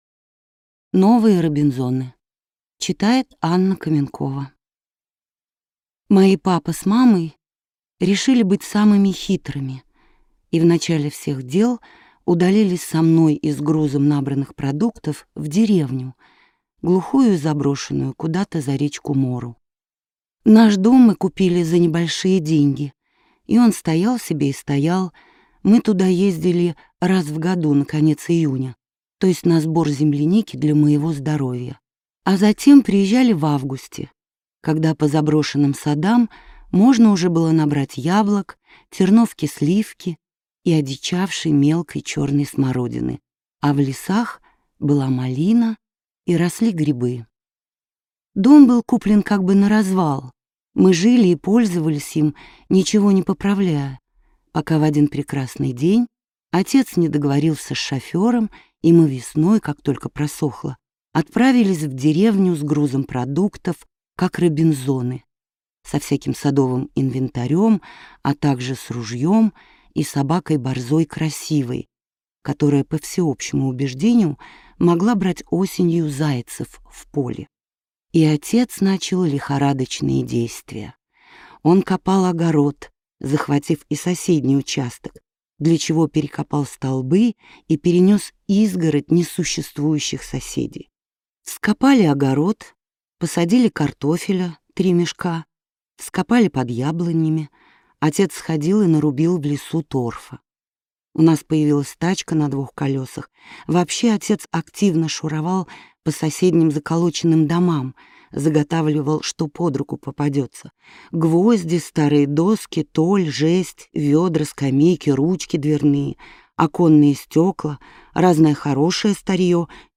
Новые Робинзоны - аудио рассказ Петрушевской - слушать